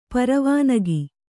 ♪ paravānagi